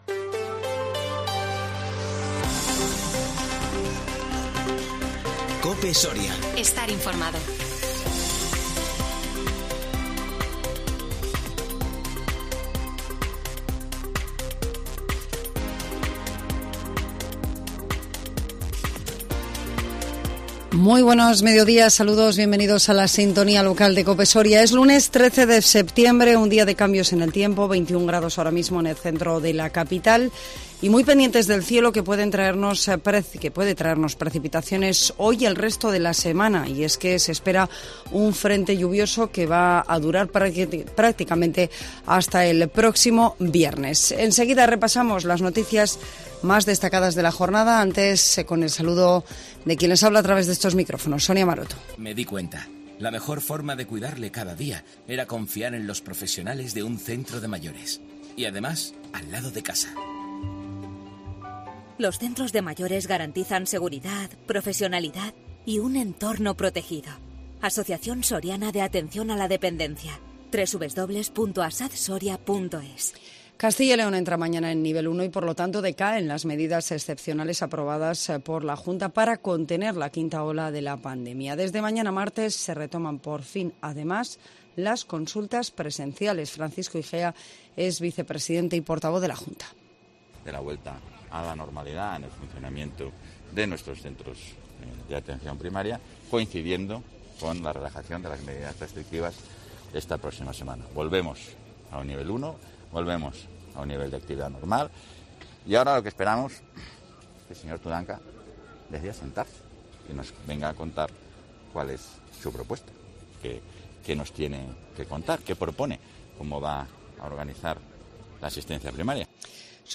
INFORMATIVO MEDIODÍA 13 SEPTIEMBRE 2021